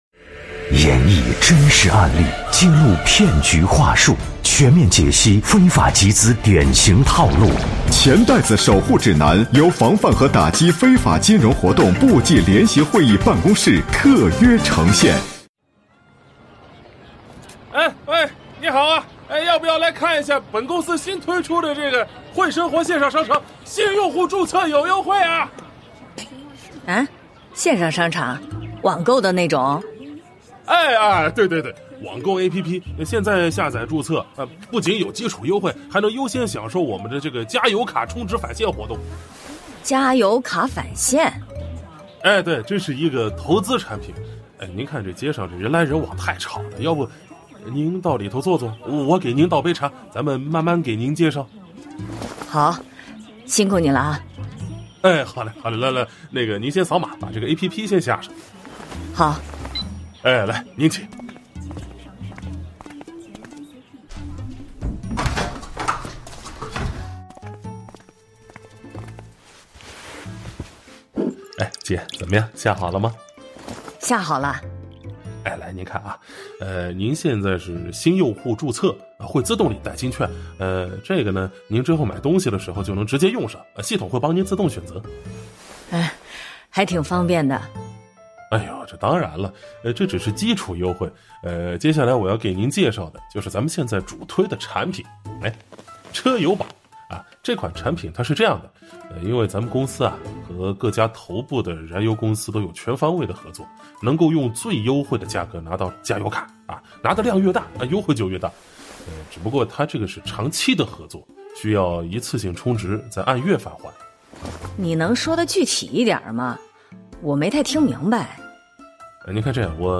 《钱袋子守护指南》是经济之声联合防范和打击非法金融活动部际联席会议办公室特别策划推出的一档防范非法集资科普栏目。选取真实案例，透过典型情节演示非法集资对个人和社会带来的危害，更生动地传递“反非”的理念，增强社会的风险意识和预防能力。